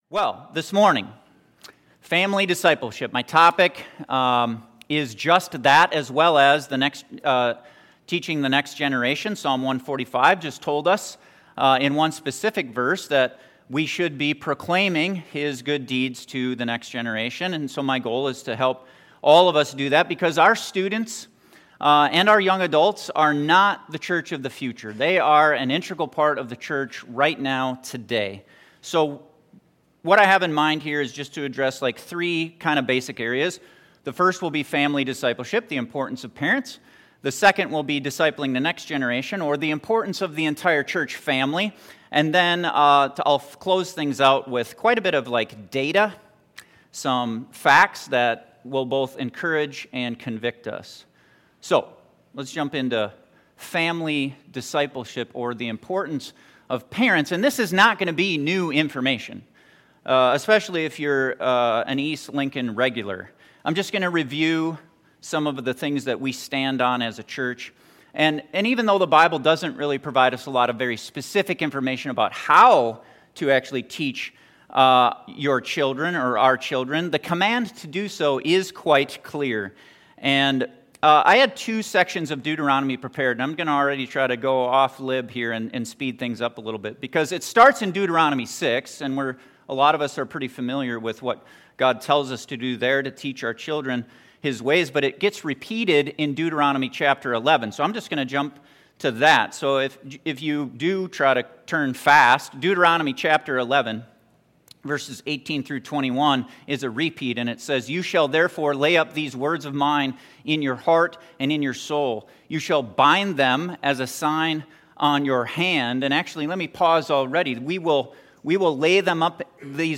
A message that looks at the importance of discipleship in our homes and in our church family.